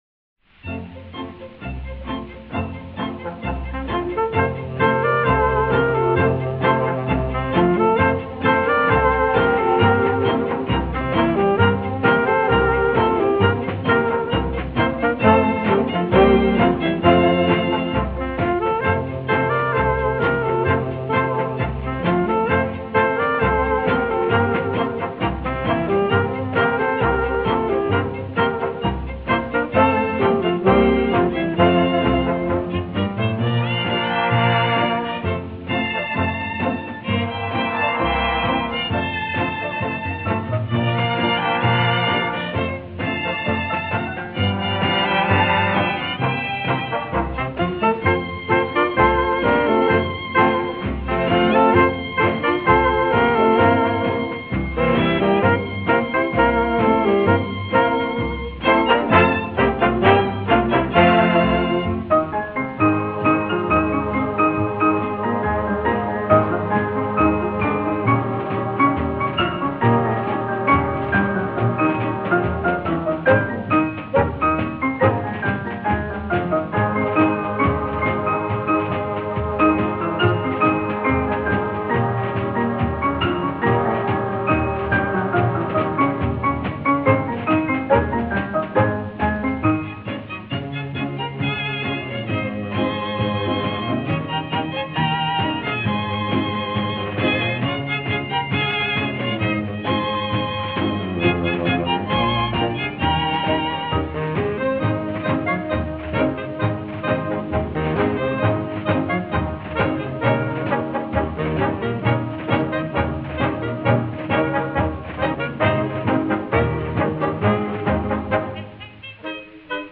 Rearranged in 2 parts harmony By